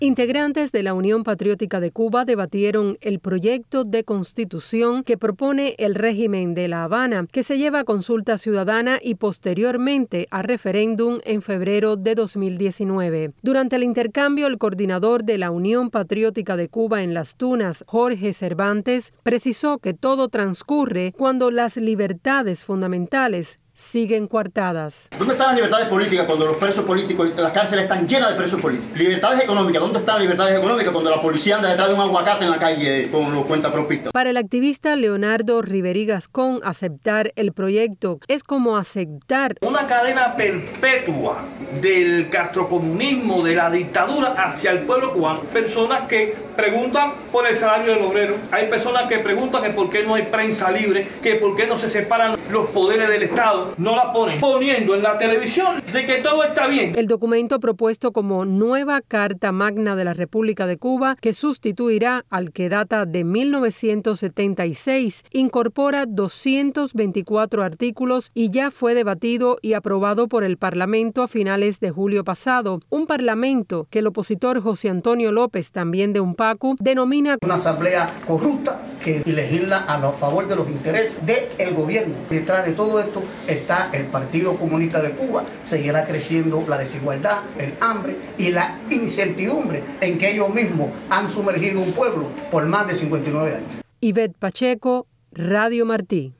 Opositores cubanos llevaron a cabo un debate abierto sobre la reforma a la Constitución que se pretende implementar para el próximo año en la isla.